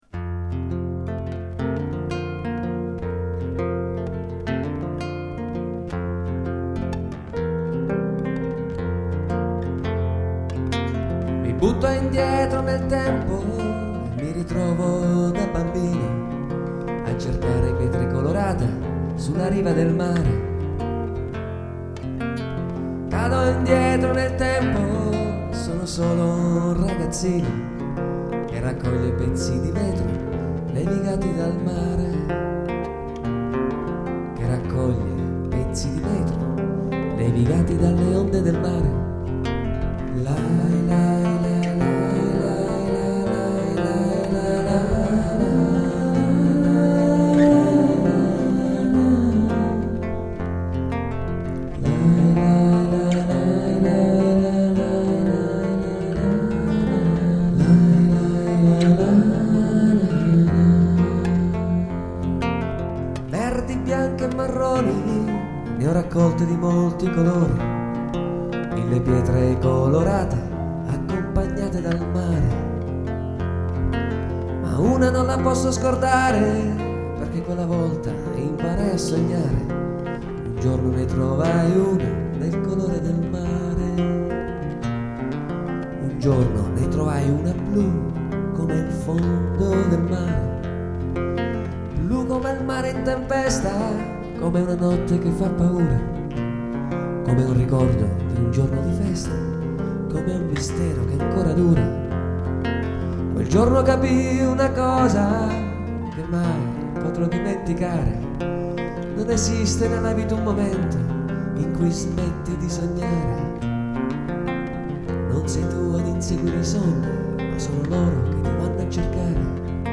Piano
Chitarra acustica